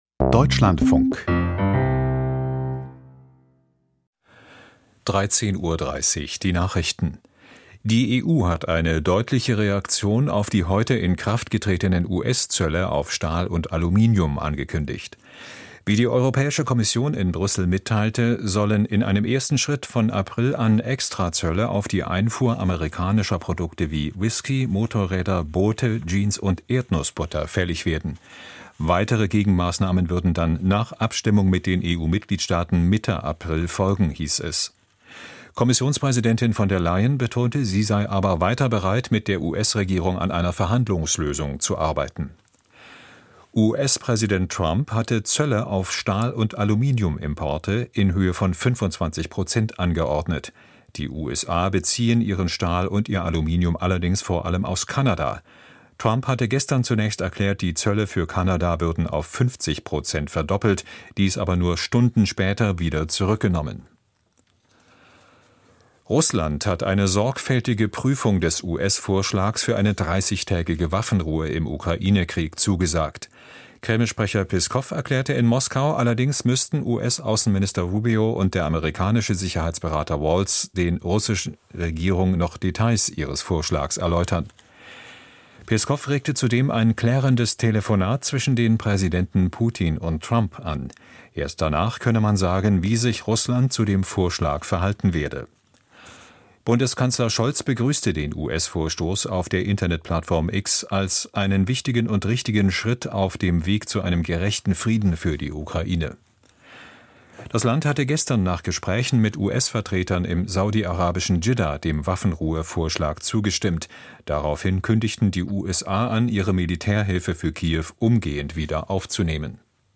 Die Deutschlandfunk-Nachrichten vom 12.03.2025, 13:30 Uhr